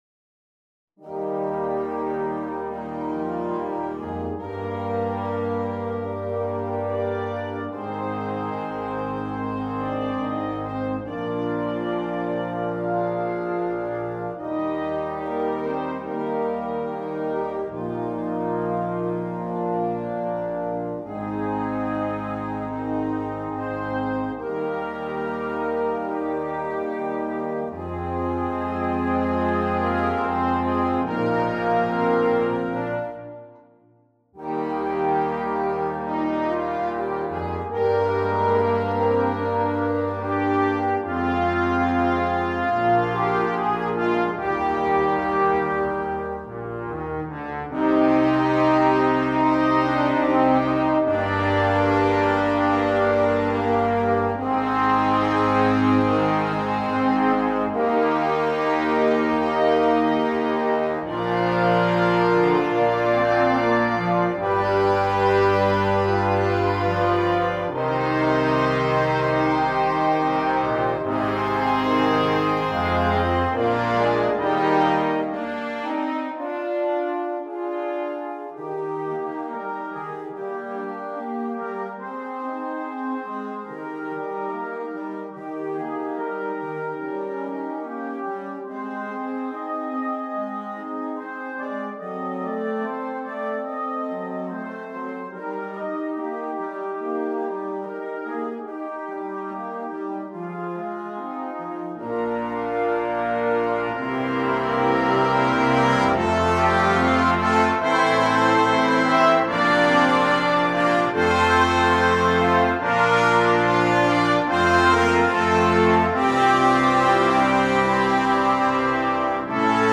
2. Concert Band
Full Band
without solo instrument
Entertainment